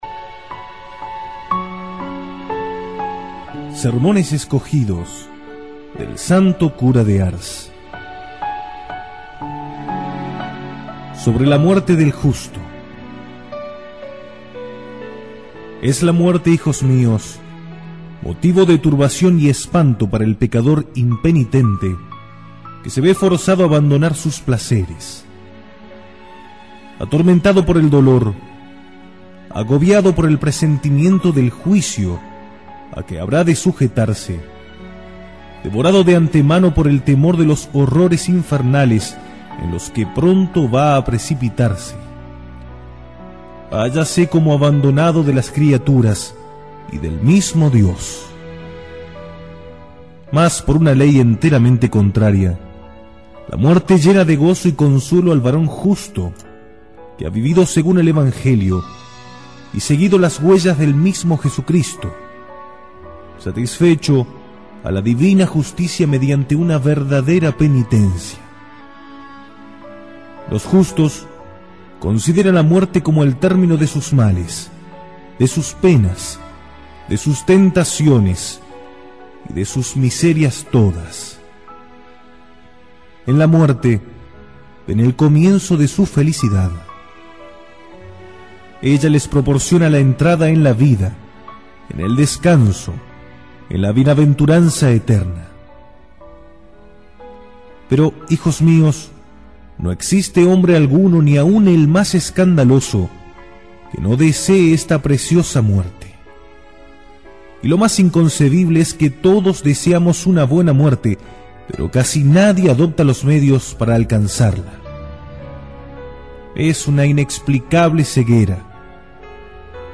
Audio–libro
Sermon-del-Santo-Cura-de-Ars-La-muerte-del-justo.mp3